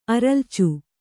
♪ aralcu